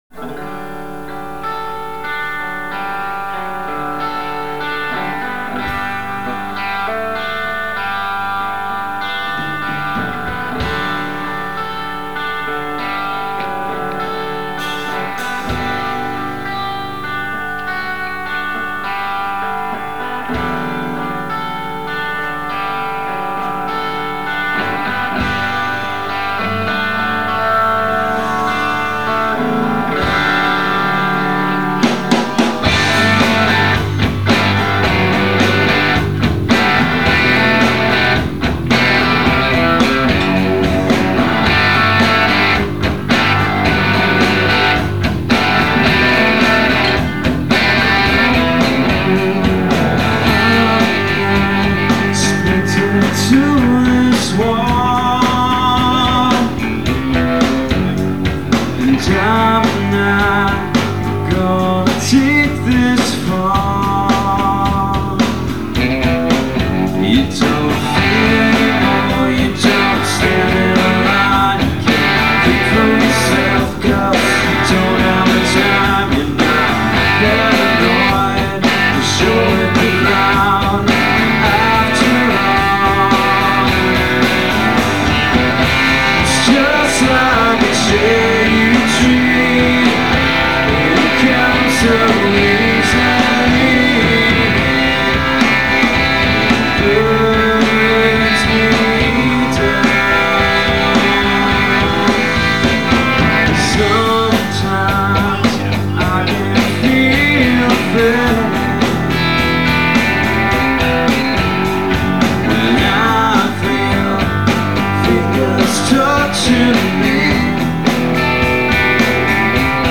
[Download - Live]